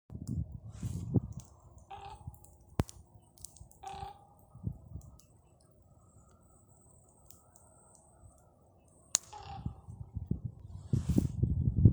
Птицы -> Вороны ->
ворон, Corvus corax